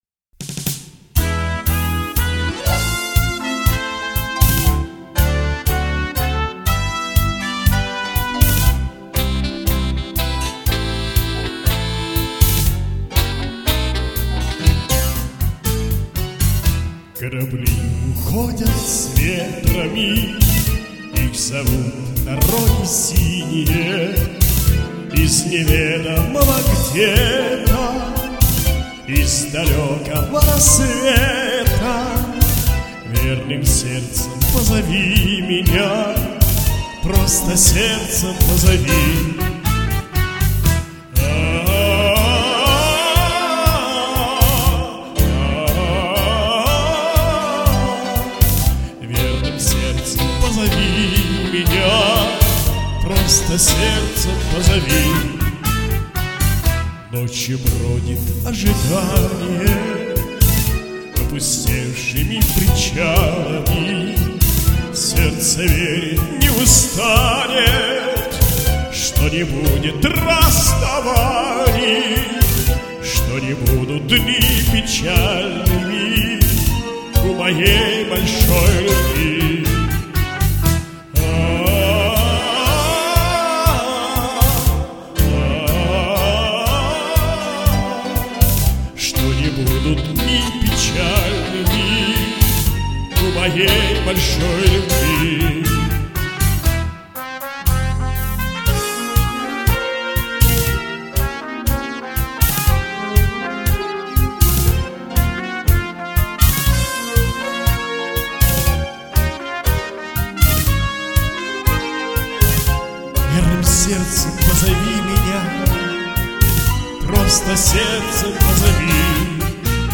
Подноминация «Бардовская песня»